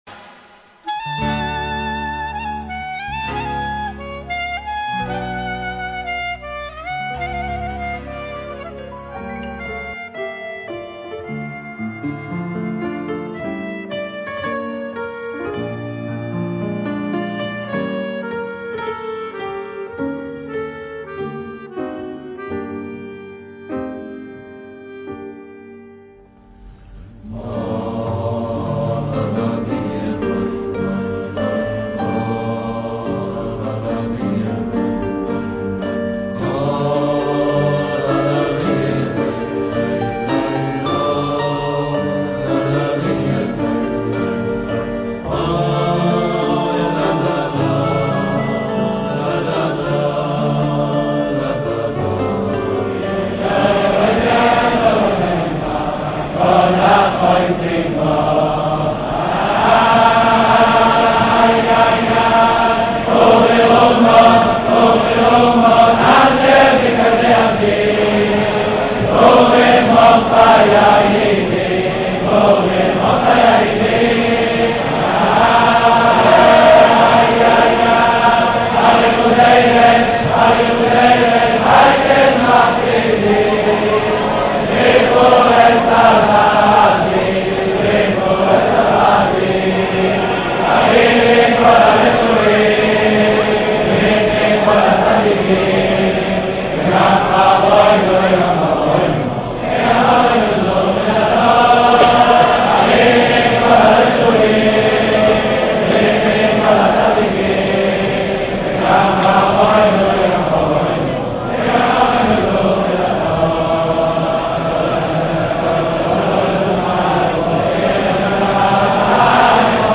Purim In Gur - 2008 - פורים בחסידות גור.mp3